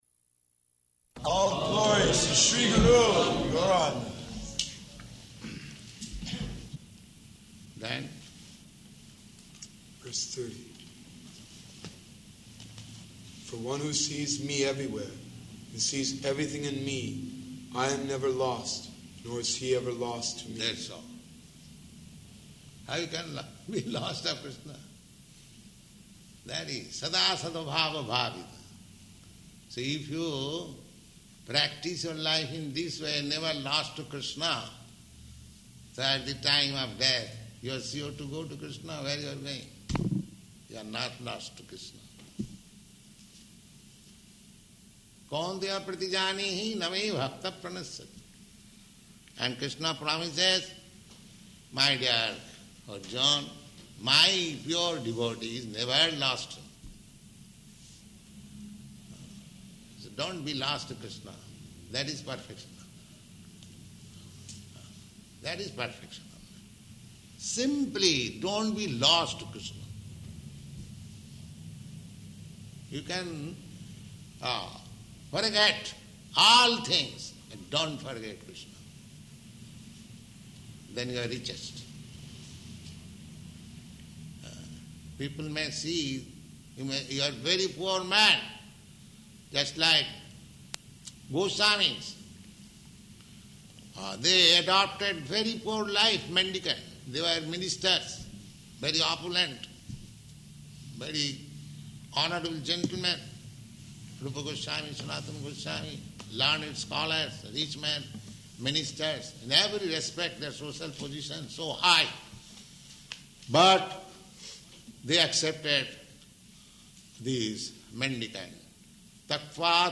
Bhagavad-gītā 6.30–34 [Sāṅkhya Yoga System] --:-- --:-- Type: Bhagavad-gita Dated: February 19th 1969 Location: Los Angeles Audio file: 690219BG-LOS_ANGELES.mp3 Devotees: All glories to Śrī Guru and Gaurāṅga.